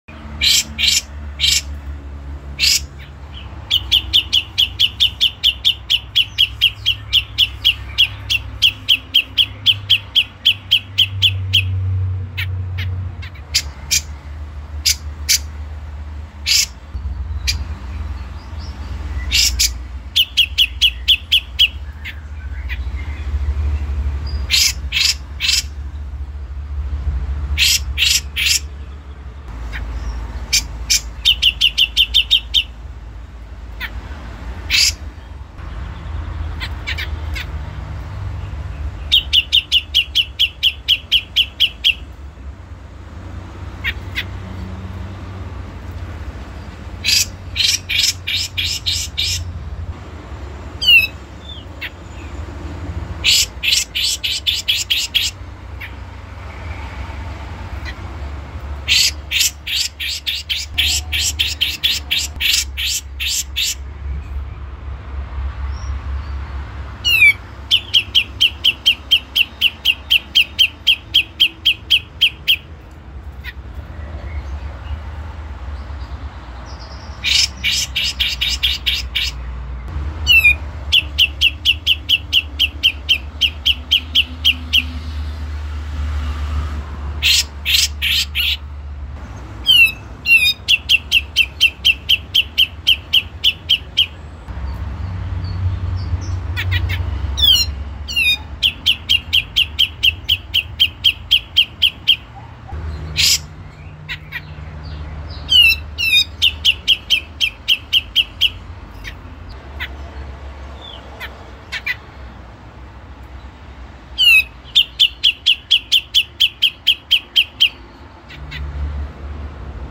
Suara Burung Cucak Jenggot Betina ini cocok untuk masteran atau pendongkrak emosi burung.
Suara Cucak Jenggot Betina
Tag: suara burung betina suara burung hias suara burung kecil suara Cucak Jenggot
Dengan panggilan birahi yang spesial, suara ini bisa membuat cucak jenggot betina langsung nyaut gacor, terutama saat manggil jantan.
suara-cucak-jenggot-betina-id-www_tiengdong_com.mp3